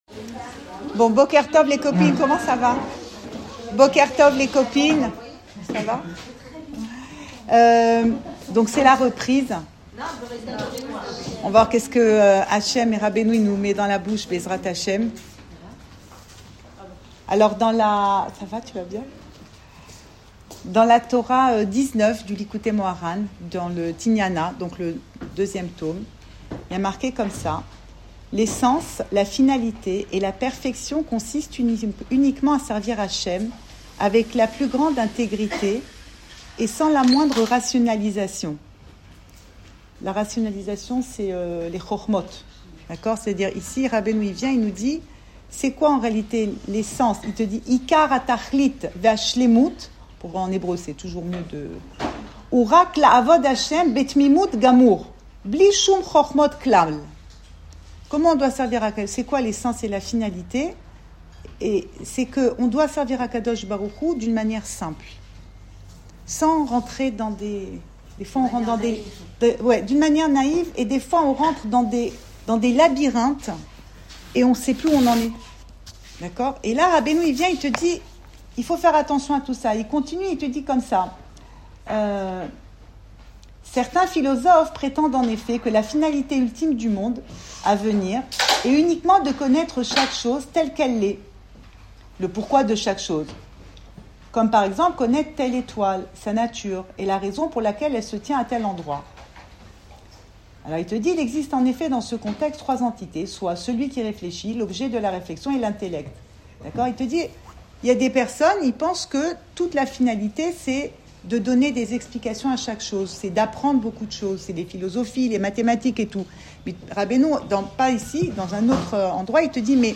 Le Hakham et le Tam (l’intelligent et le simple) n°1 Cours audio Le coin des femmes Le fil de l'info Pensée Breslev - 22 octobre 2025 29 octobre 2025 Le Hakham et le Tam n°1. Enregistré à Tel Aviv